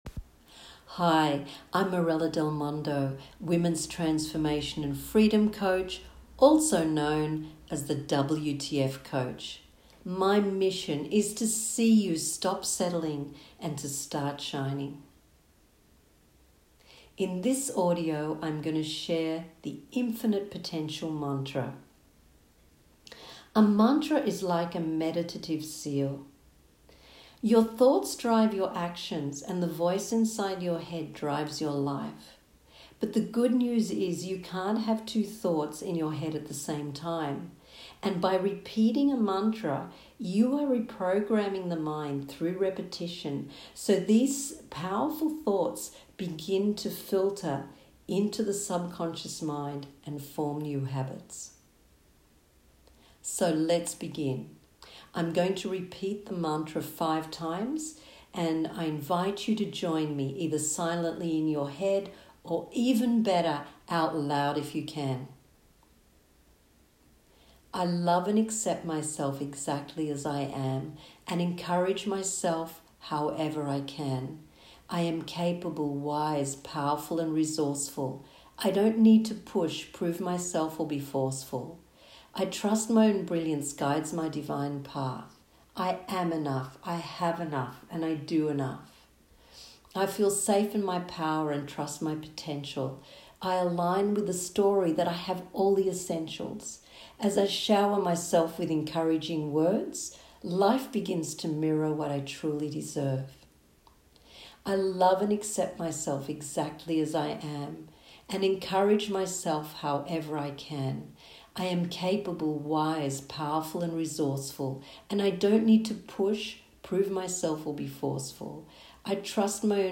I recorded these short mantras for myself to keep me on track!
Infinite Potential Mantra
Infinite+potential+Mantra.m4a